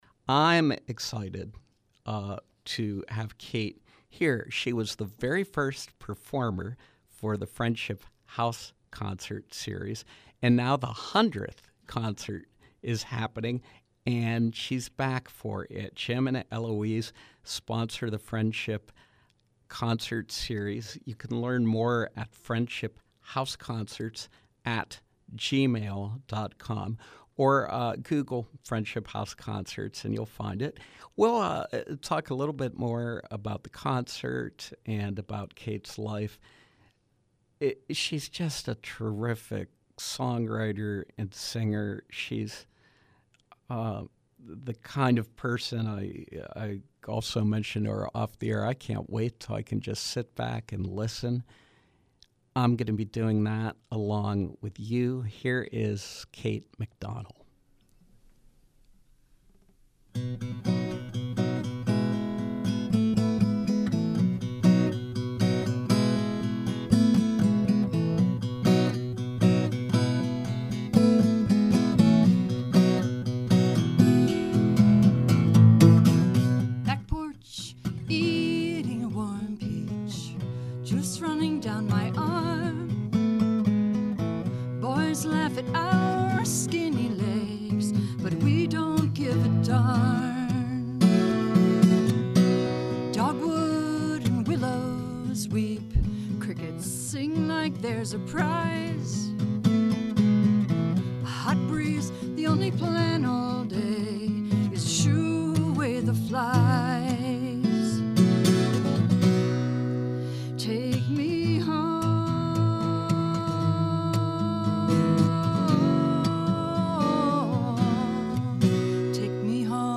Live performance